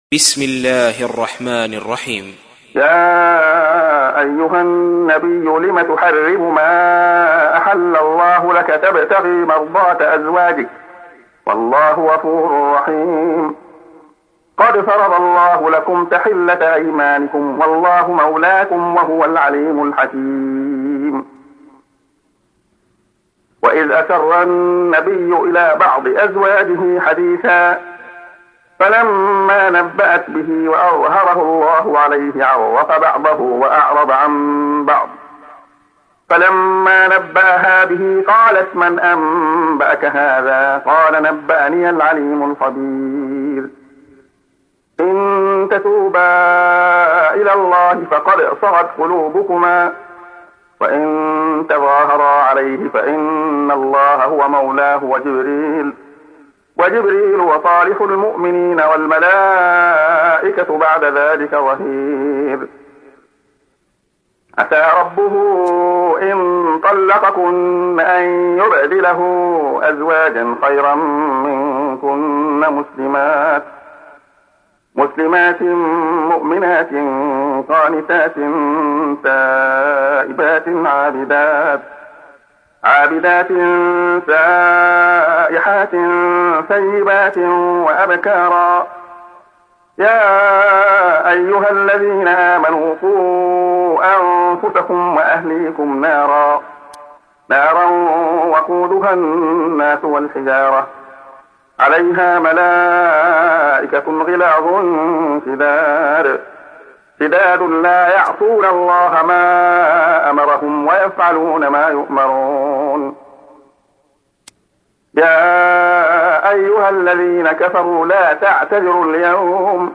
تحميل : 66. سورة التحريم / القارئ عبد الله خياط / القرآن الكريم / موقع يا حسين